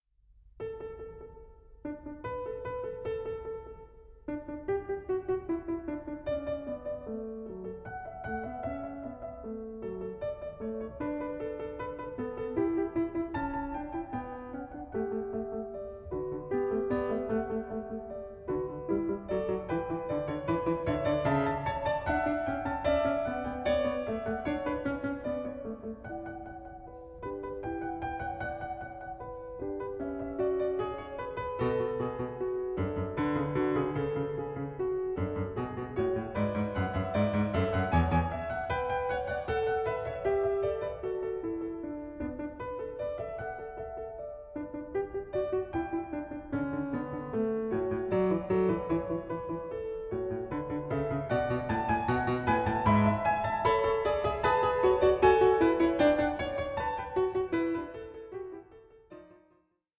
9) No. 5, in D Major: Prelude 1:49